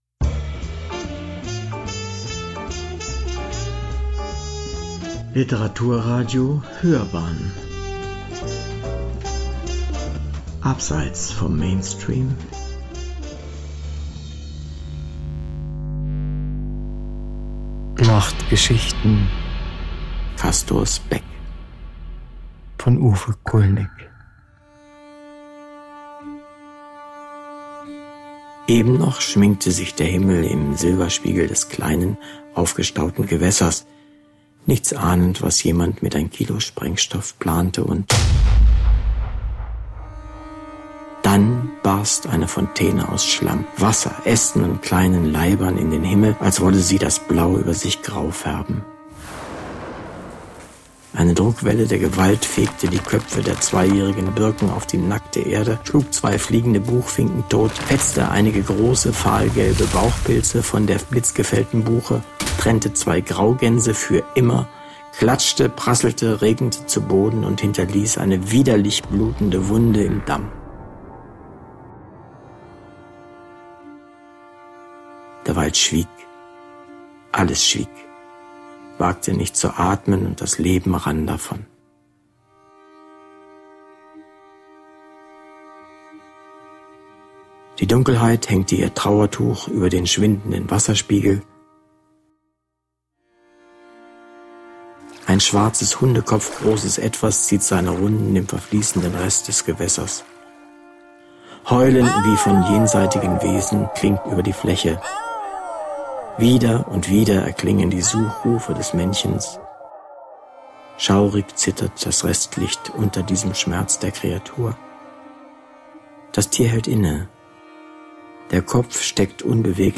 Autor, Sprecher, Realisation: